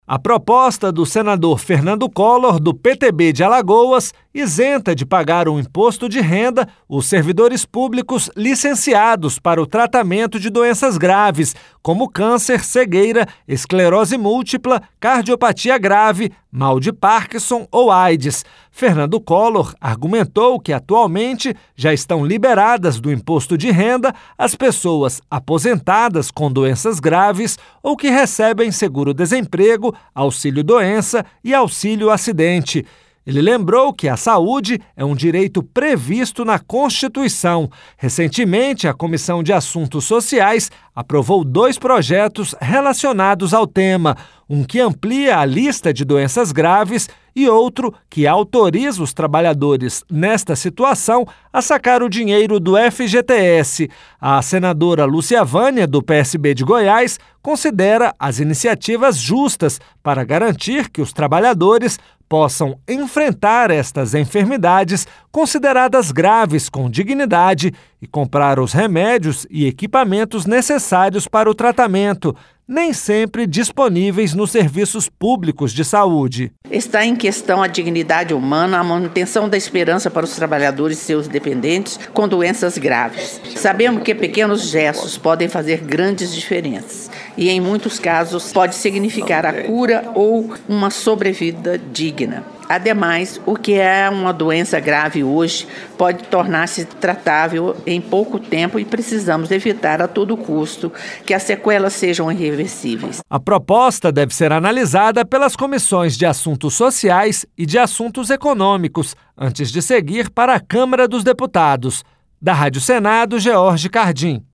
Transcrição